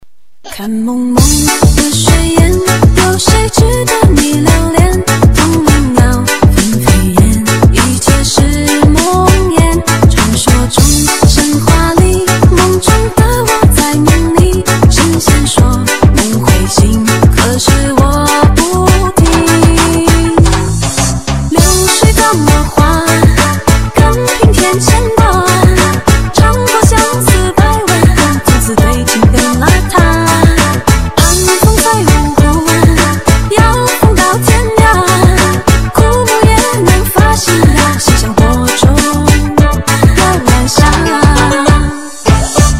分类: DJ铃声